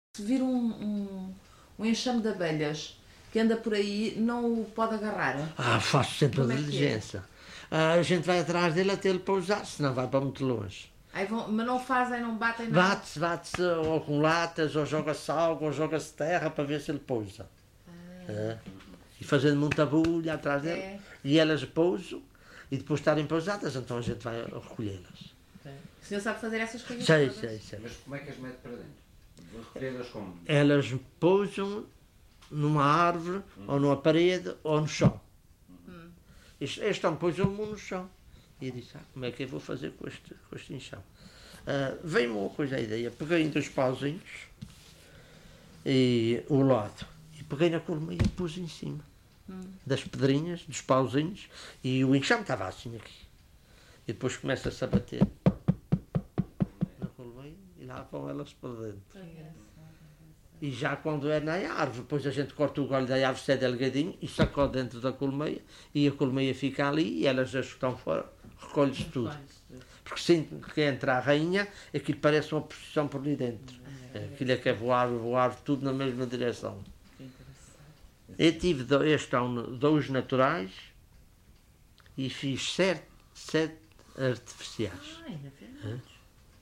LocalidadePedras de São Pedro (Vila do Porto, Ponta Delgada)